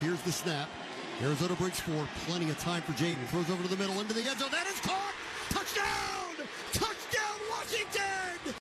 PBP Commanders 33-14-McLaurin 10 Yd Rec TD